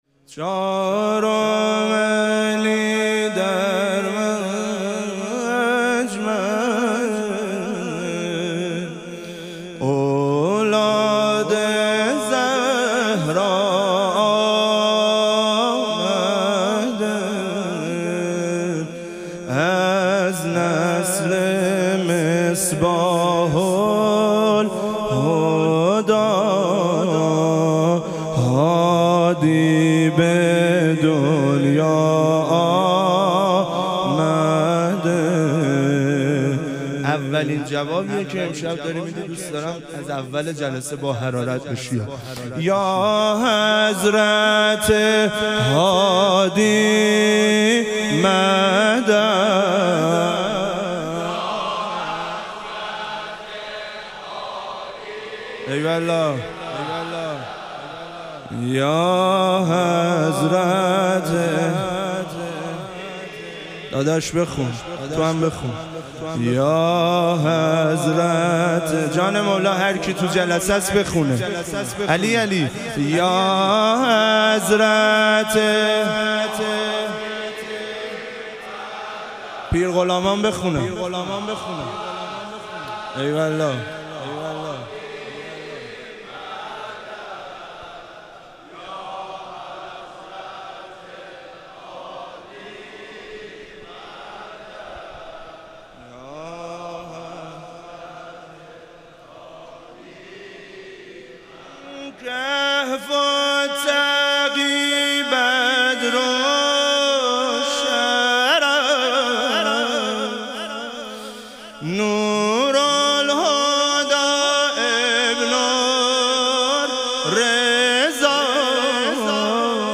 ظهوروجود مقدس امام هادی علیه السلام - مدح و رجز